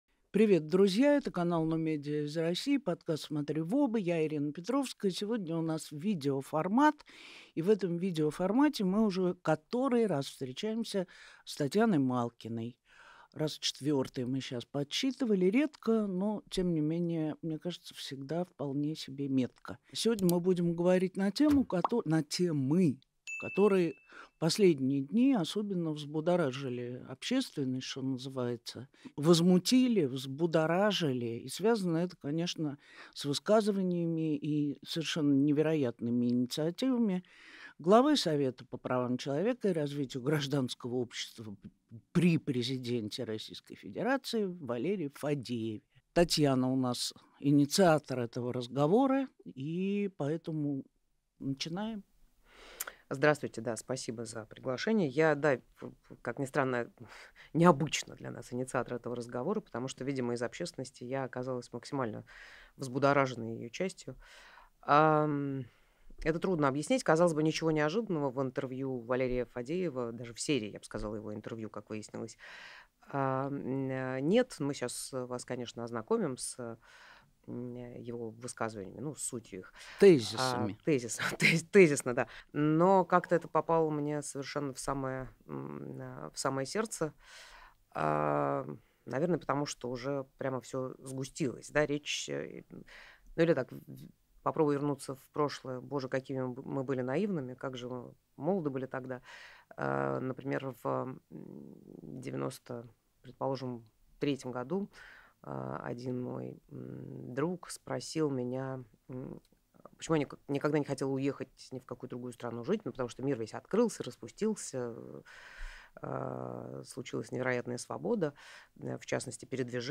Эфир ведёт Ирина Петровская